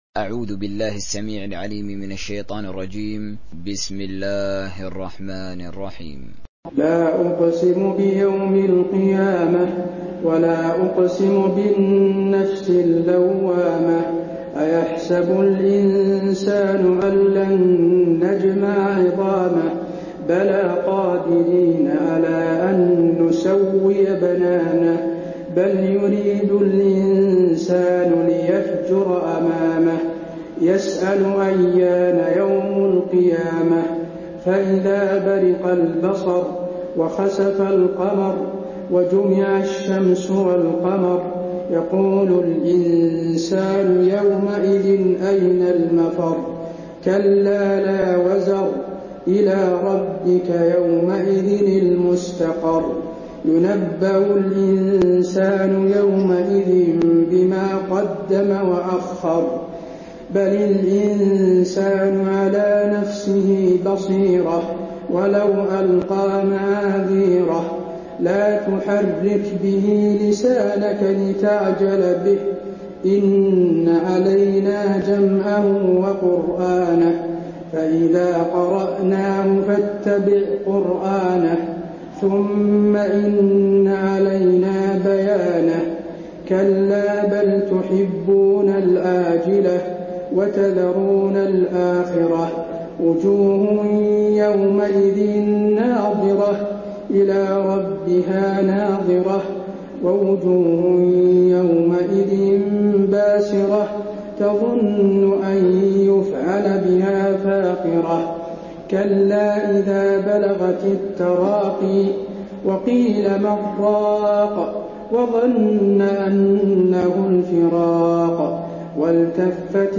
دانلود سوره القيامه mp3 حسين آل الشيخ تراويح (روایت حفص)
دانلود سوره القيامه حسين آل الشيخ تراويح